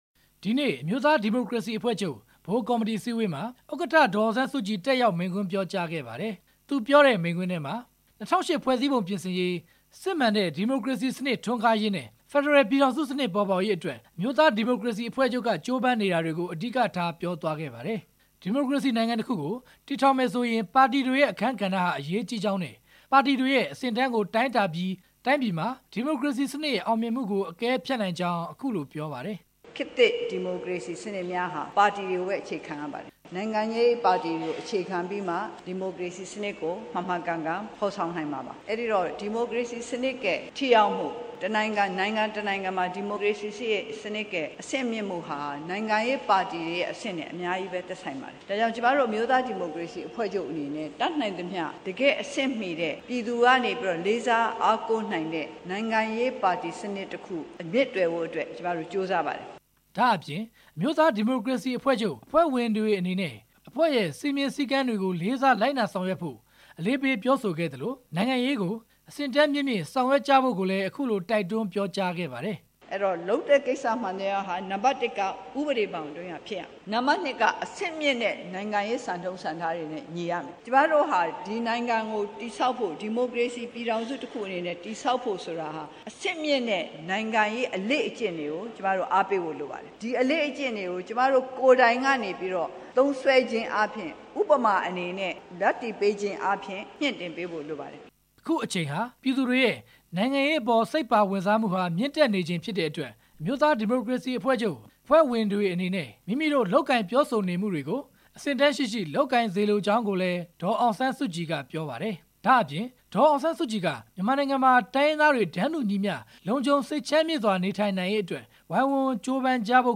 ဒီနေ့ ရန်ကုန်မြို့ ဗဟန်းမြို့နယ် ဒို့ရိုးရာ စားသောက်ဆိုင် မှာ ကျင်းပတဲ့ အမျိုးသားဒီမိုကရေစီအဖွဲ့ချုပ် တတိယ အကြိမ် ဗဟိုကော်မတီ အစည်းအဝေးမှာ ဒေါ်အောင် ဆန်းစုကြည်က အခုလို ပြောခဲ့တာပါ။